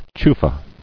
[chu·fa]